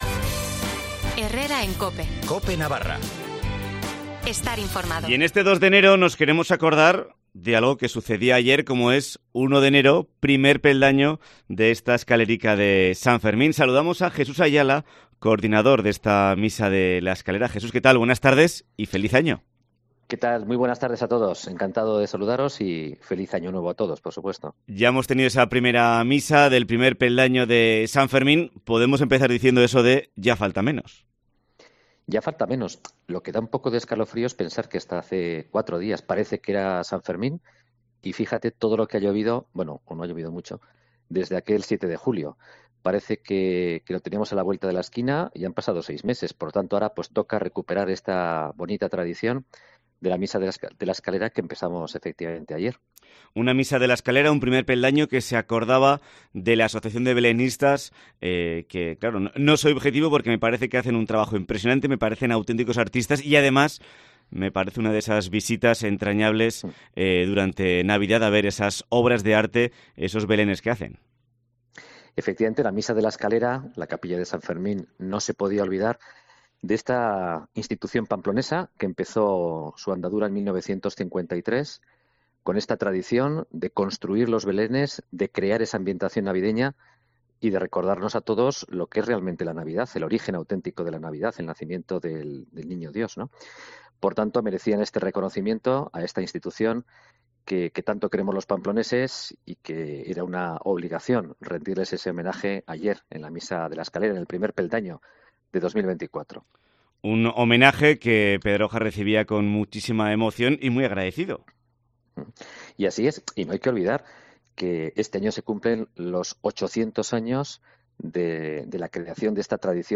Primera Misa de la escalera de San Fermín de 2024